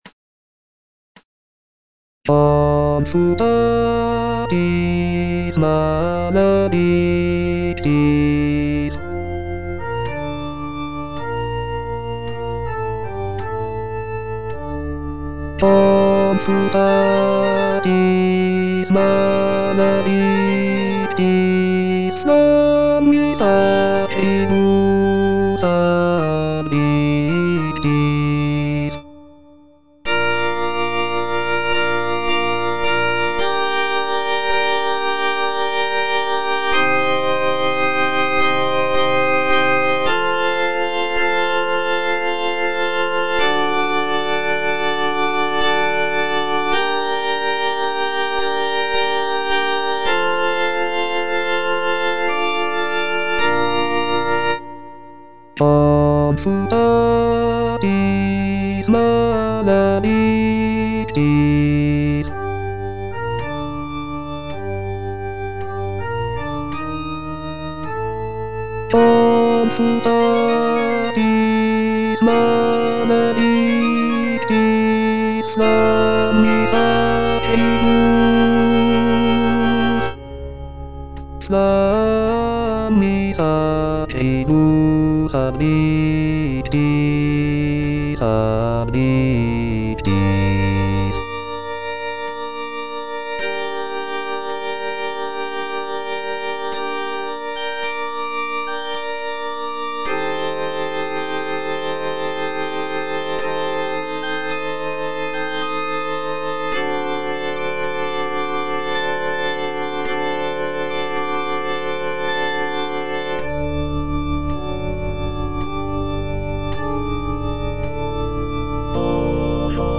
F. VON SUPPÈ - MISSA PRO DEFUNCTIS/REQUIEM Confutatis (bass II) (Voice with metronome) Ads stop: auto-stop